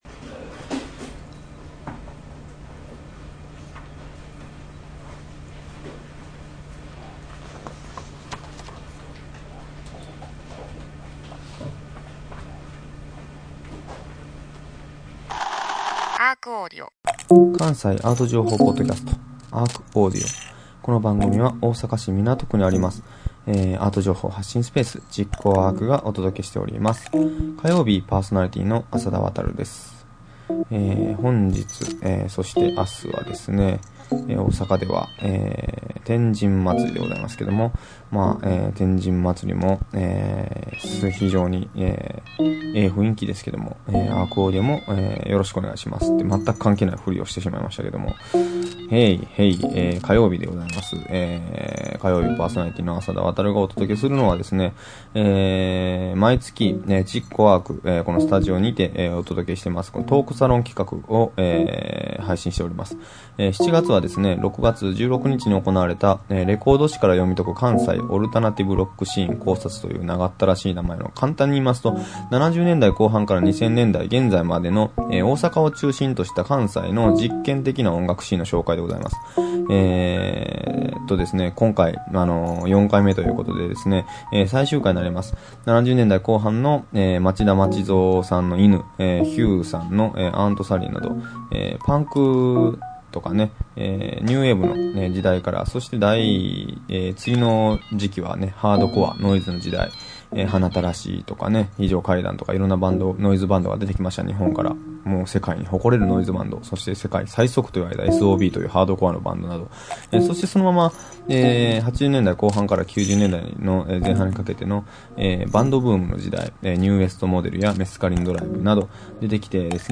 毎週火曜日は築港ARCにて毎月開催されるARCトークコンピレーションの模様を全4回に分けてお届けします。